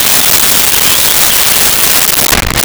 Kiss With Pop 02
Kiss With Pop 02.wav